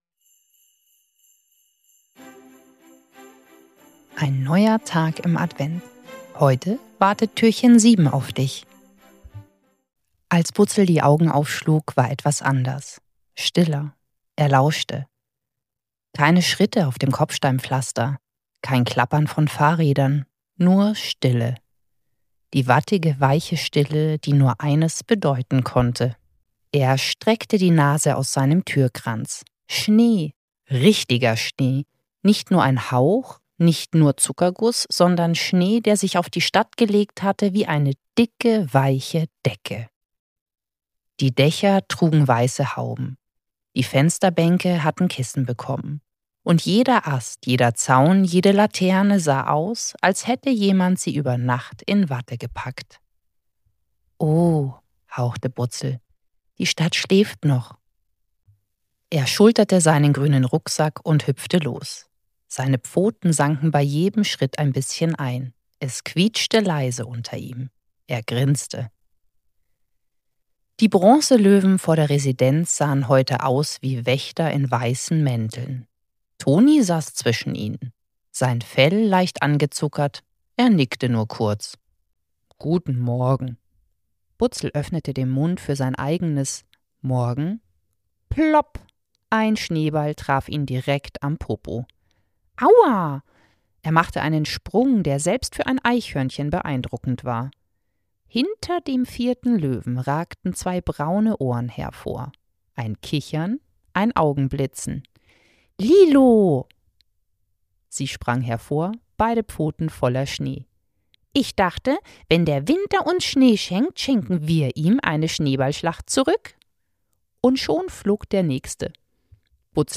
7. Türchen - Butzel und die Bücherkisten-Katze ~ Butzels Adventskalender – 24 Hörgeschichten voller Herz & kleiner Wunder Podcast